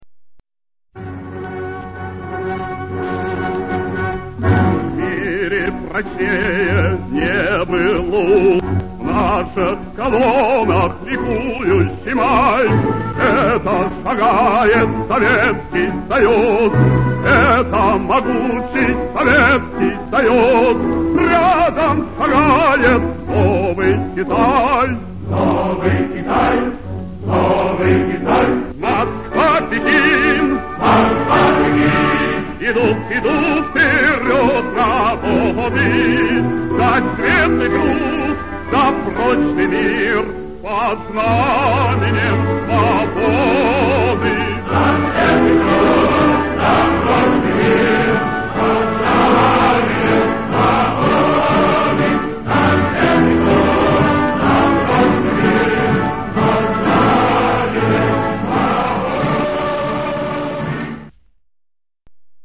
russian music